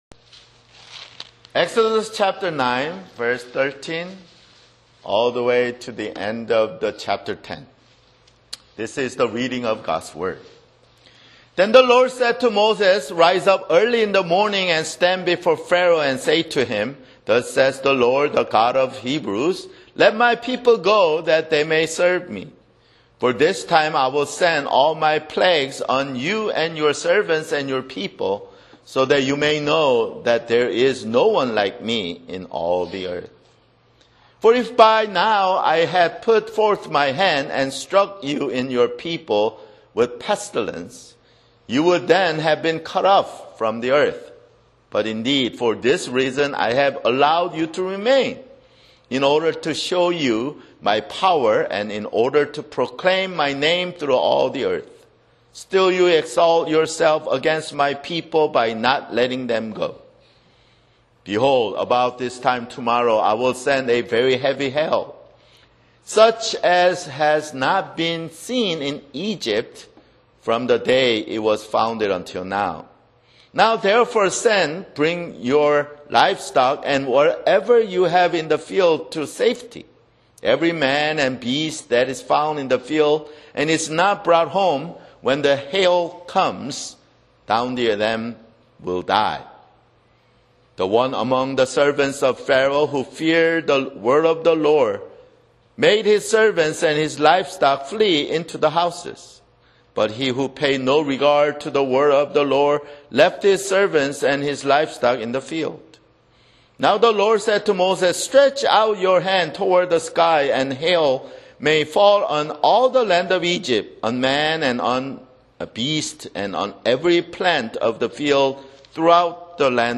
[Sermon] Exodus (23)